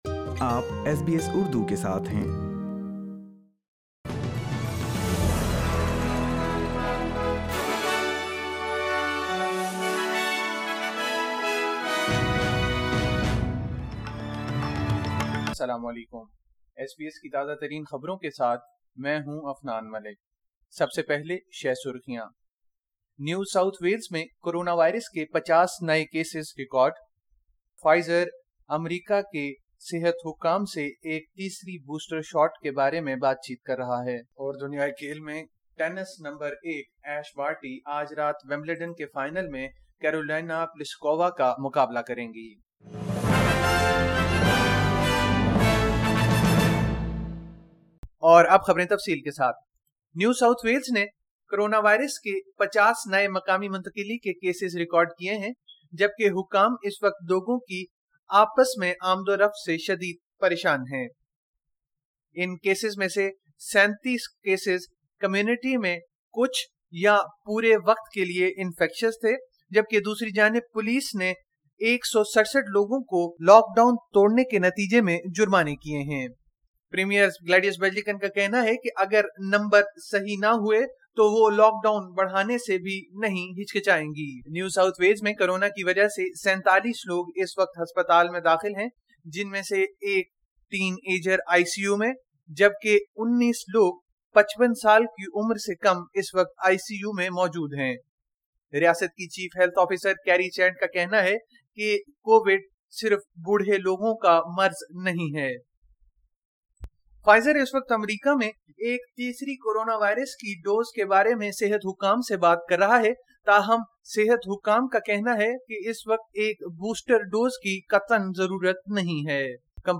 SBS Urdu News 10 July 2021